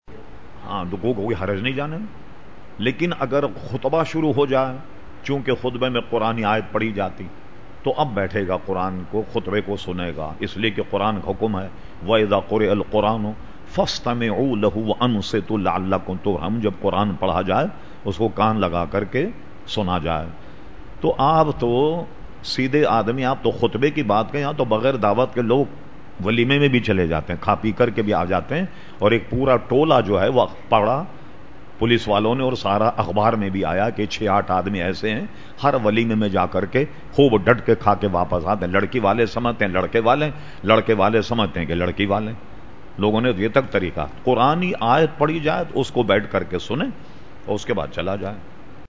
Q/A Program held on Sunday 26 September 2010 at Masjid Habib Karachi.